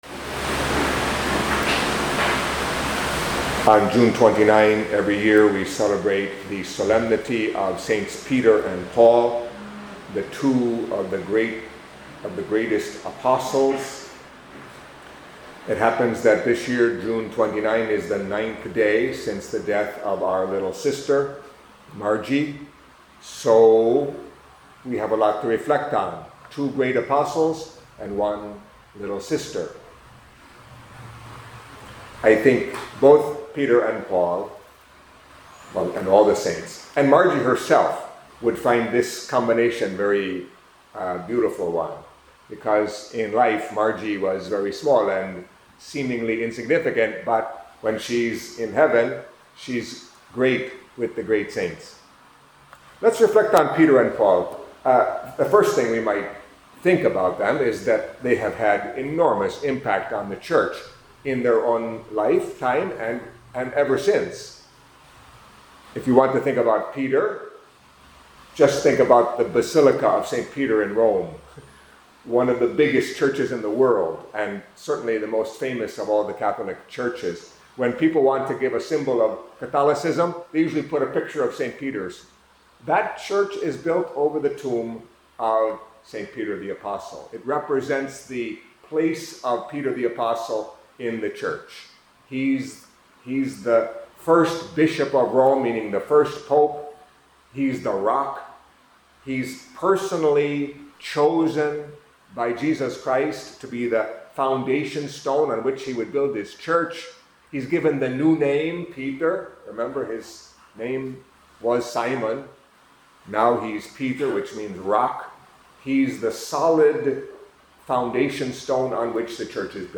Catholic Mass homily for Thursday of the Twelfth Week in Ordinary Time